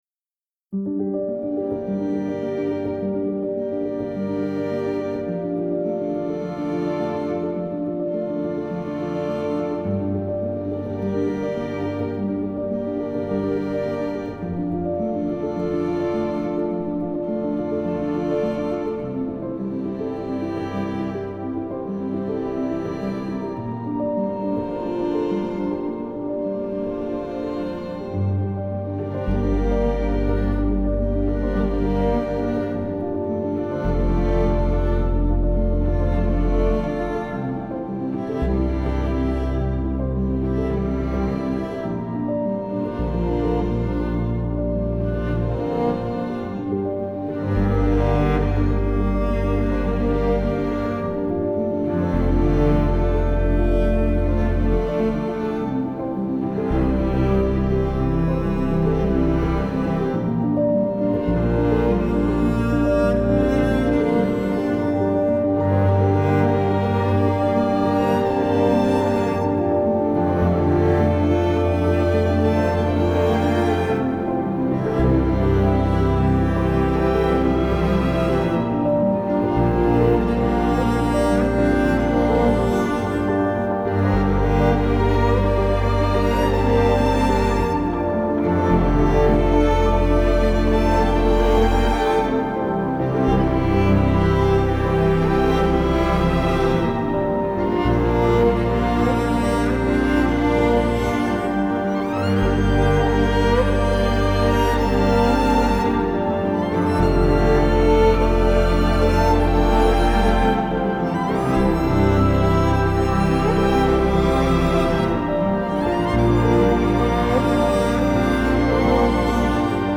آرامش بخش , الهام‌بخش , پیانو , عصر جدید , موسیقی بی کلام
موسیقی بی کلام زهی